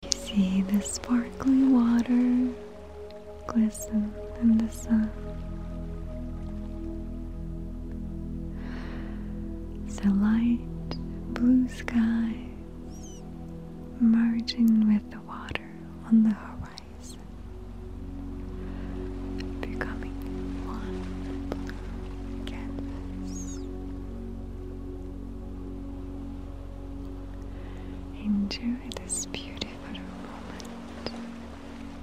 Thank you Ocean Meditation (A 12 minute long guided meditation into a deep state of relaxation using Delta Wave ambient music, ocean waves sounds, softly spoken script.
OceanMeditationSample.mp3